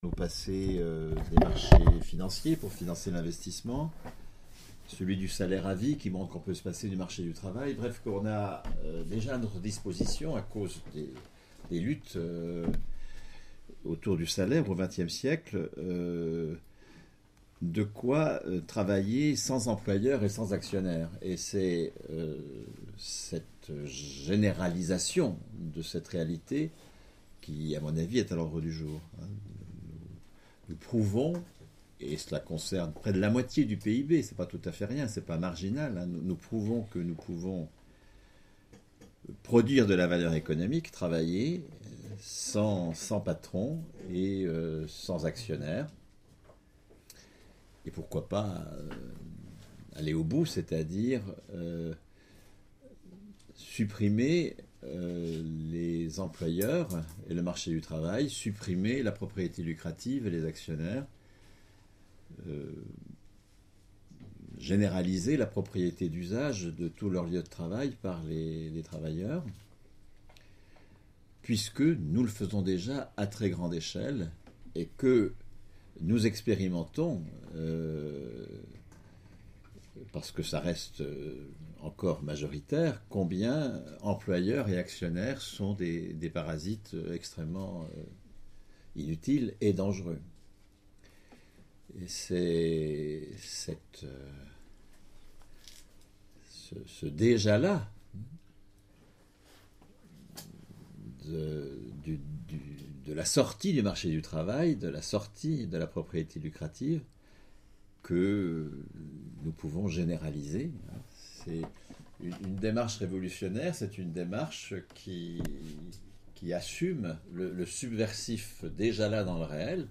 Conférence suivie d'un débat avec Bernard Friot, économiste et sociologue, auteur de L'Enjeu des retraites (2010), L'Enjeu du salaire (2012) et Puissances du salariat (2012).